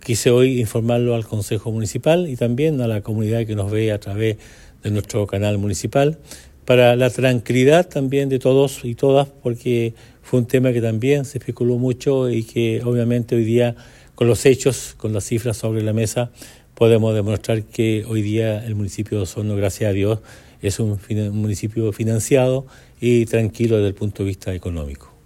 Además, el alcalde enfatizó que la transparencia en la administración pública es uno de los pilares fundamentales de su gobierno, y destacó que este informe refleja el compromiso de su administración con el bienestar de la comunidad y la correcta utilización de los recursos públicos.